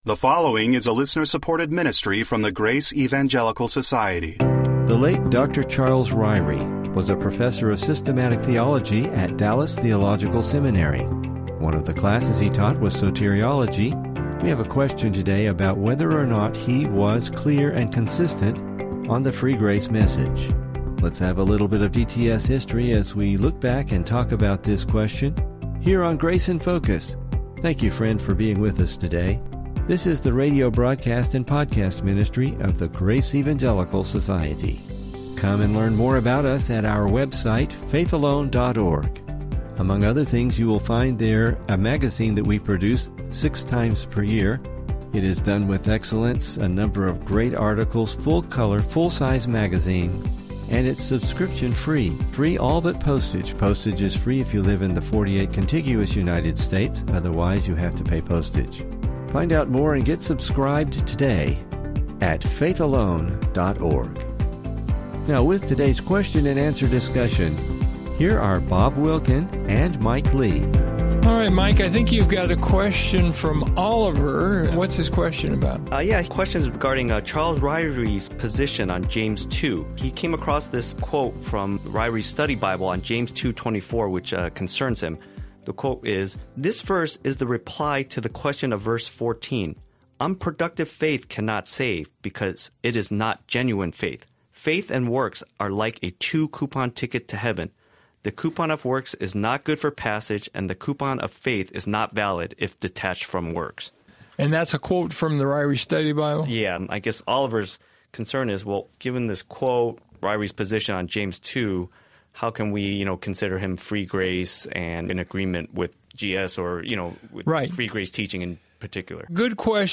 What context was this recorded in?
Welcome to Grace in Focus radio.